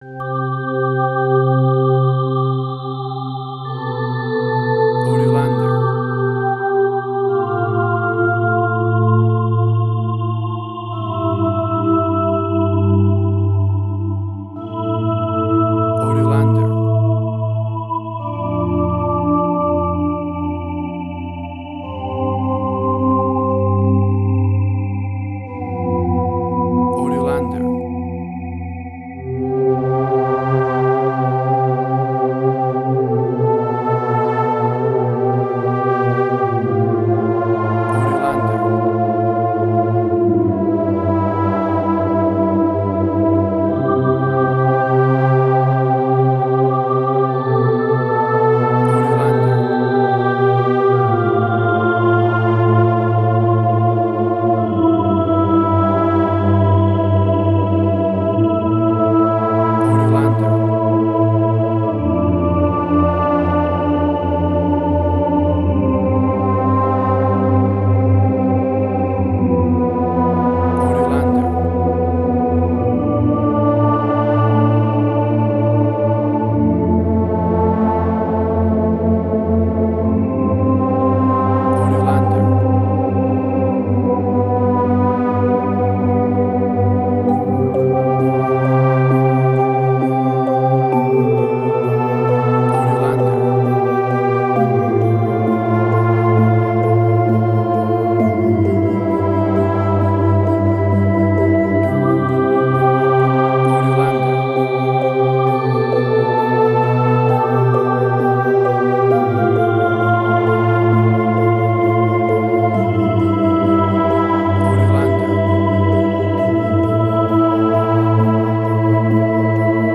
New Age.
Tempo (BPM): 66